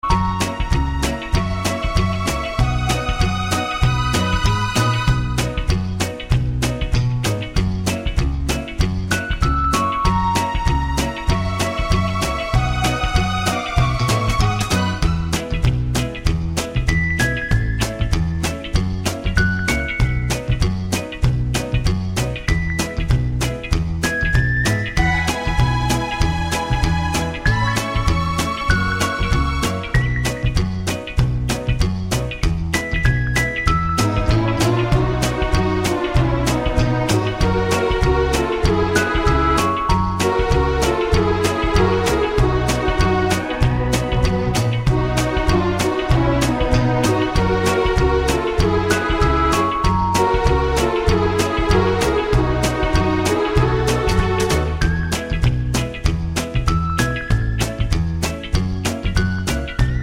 no Backing Vocals Country (Female) 2:20 Buy £1.50